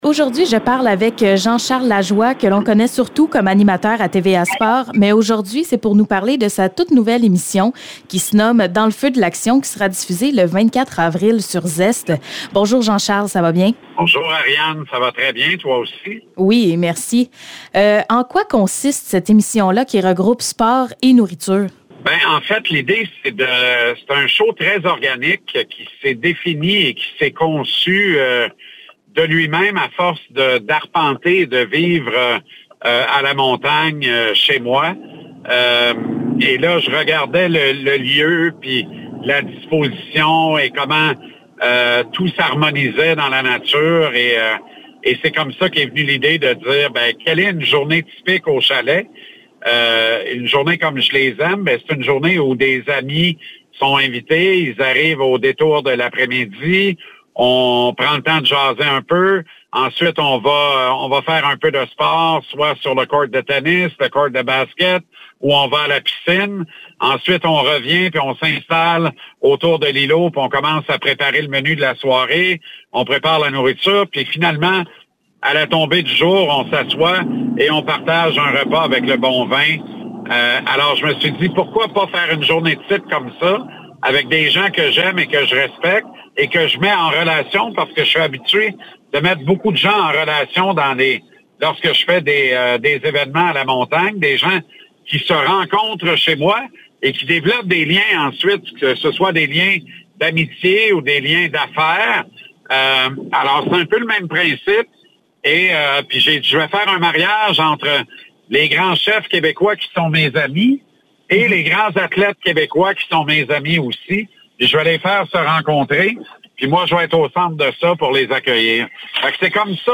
Entrevue avec Jean-Charles Lajoie